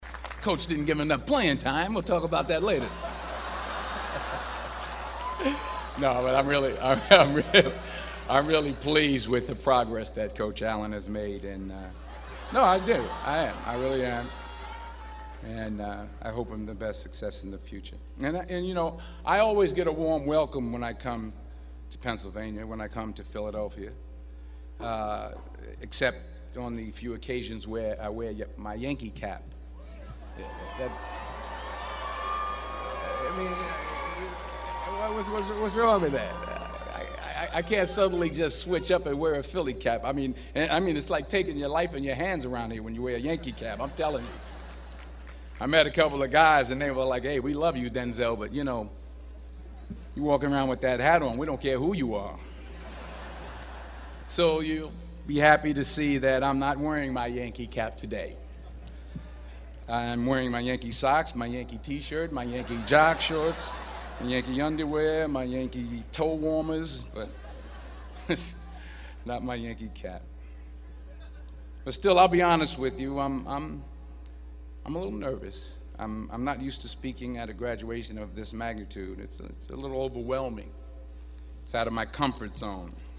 公众人物毕业演讲第418期:丹泽尔2011宾夕法尼亚大学(2) 听力文件下载—在线英语听力室